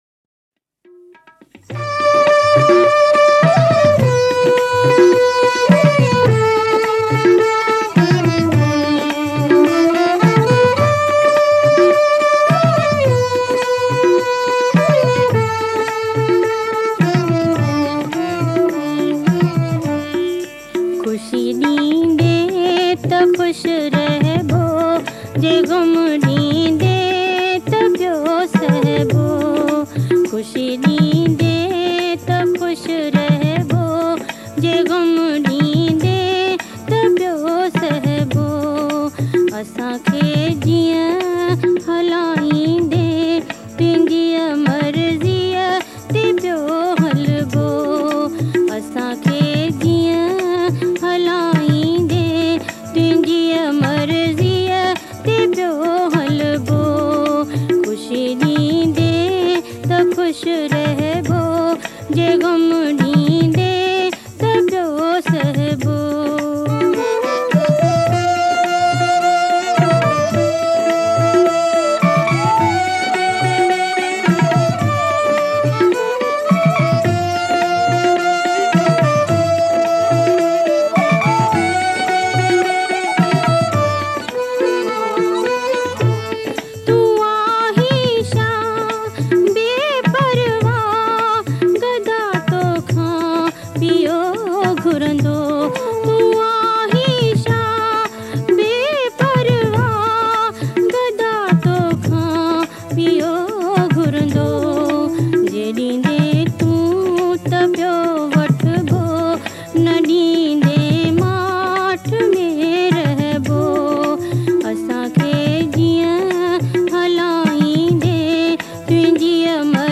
Sindhi Jhulelal Geet, Lada, Kalam, Ghazal & Bhajans